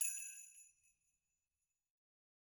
Sleighbells_Hit_v1_rr2_Mid.wav